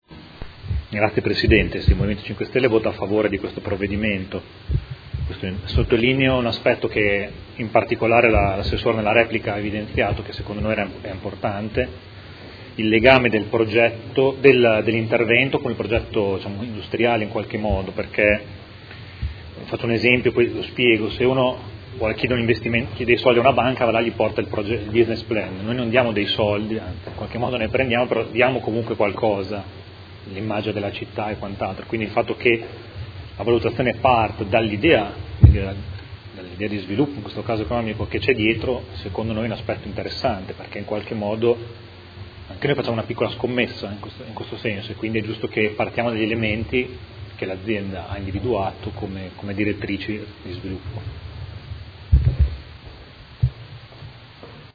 Mario Bussetti — Sito Audio Consiglio Comunale
Seduta del 27/10/2016 Dichiarazione di voto.